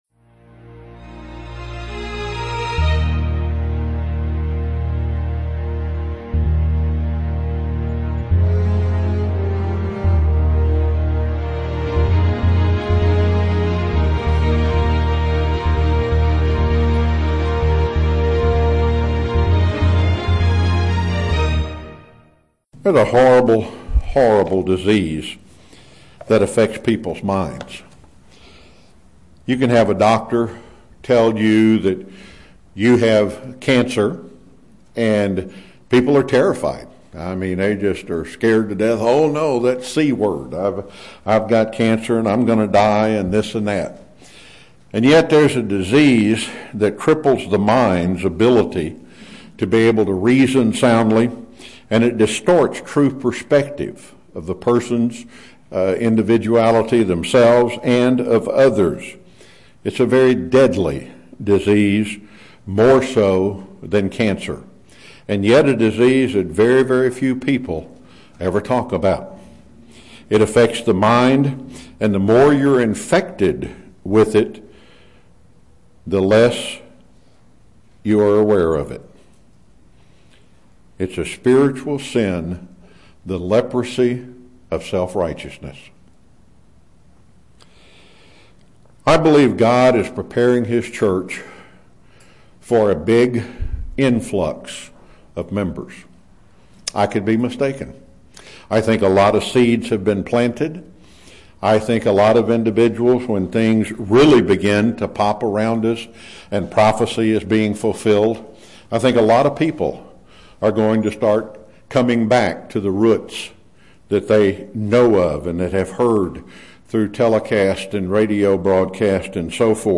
Given in Chattanooga, TN
UCG Sermon Studying the bible?